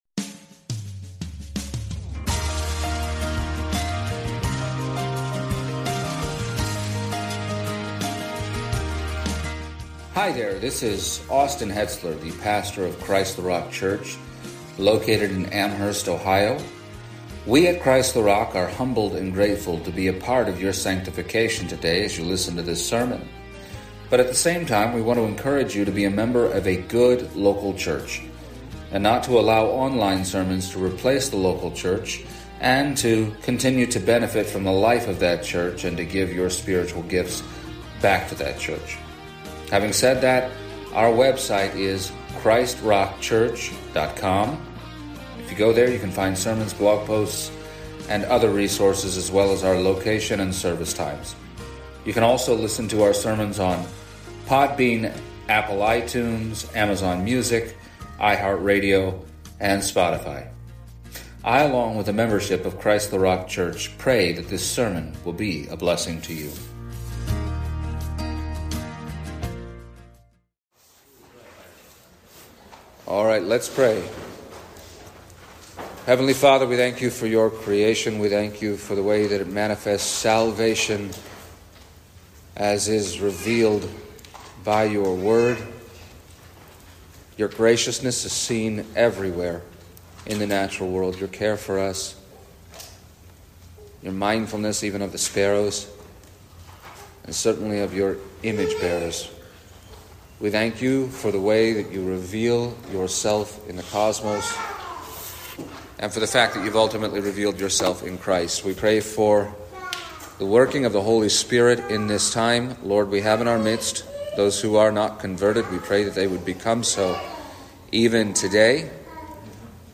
The second of two messages given at our annual CtRC church Camp
Service Type: Special event